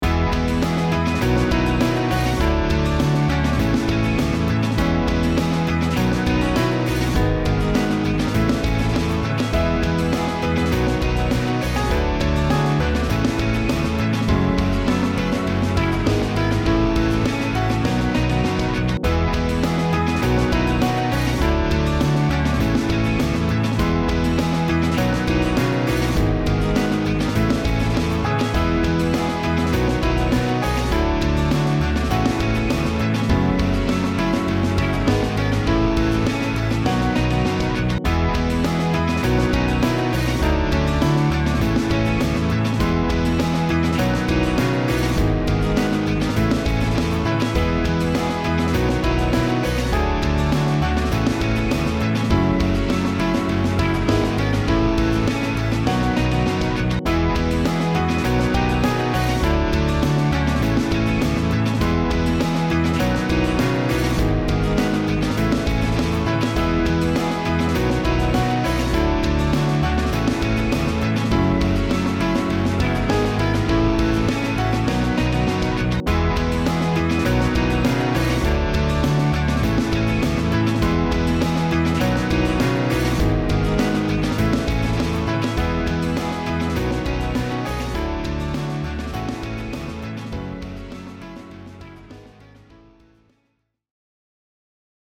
ショートスローテンポポップ